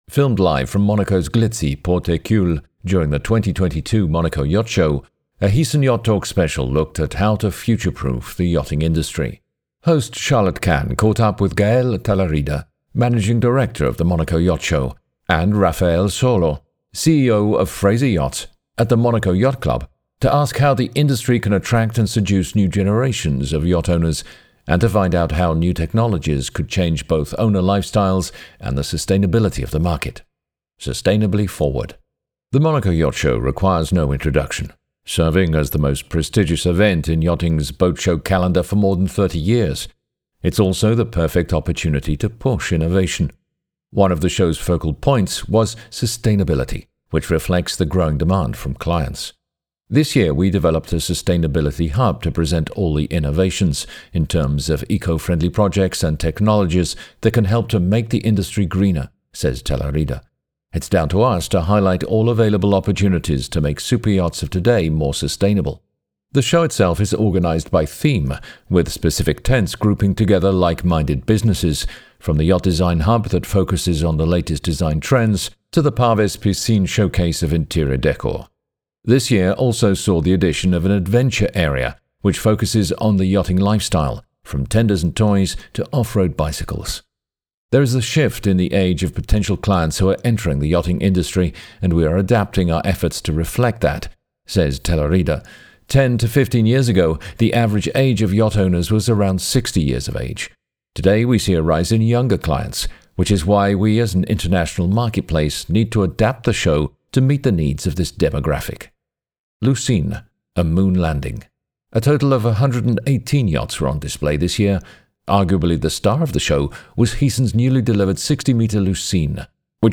Filmed live from Monaco’s glitzy Port Hercule during the 2022 Monaco Yacht Show, a Heesen YachtTalk special looked at how to future proof the yachting industry.